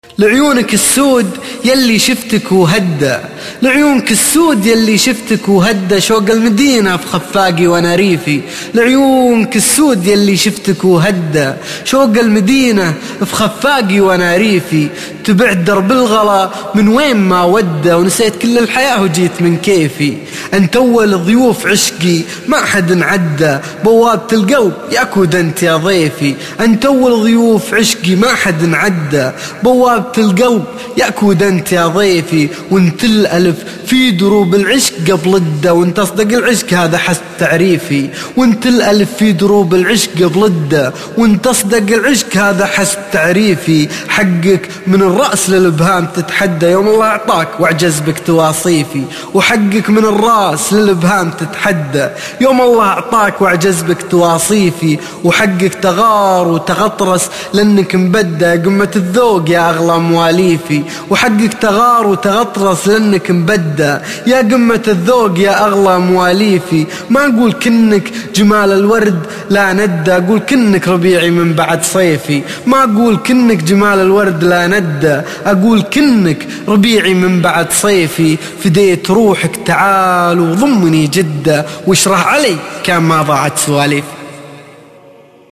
لعيونك السود - القاء